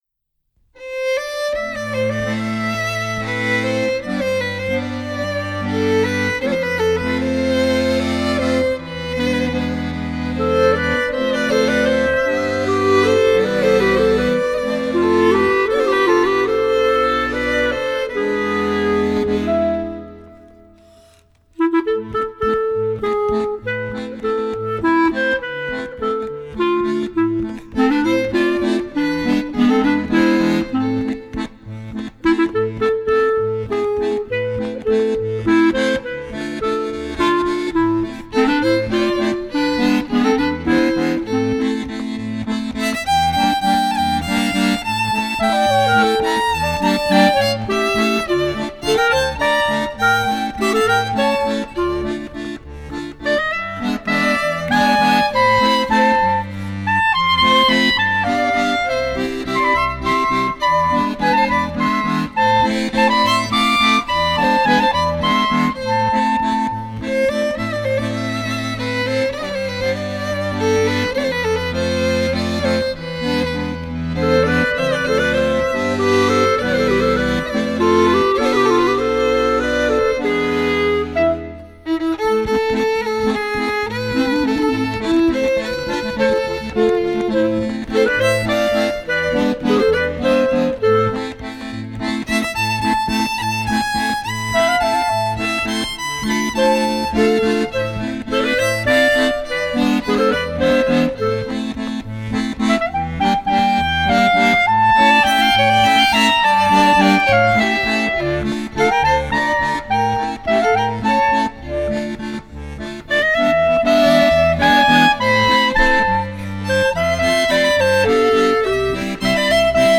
trío feminino
clarinete e clarinete baixo
violín